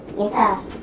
speech
keyword-spotting
speech-commands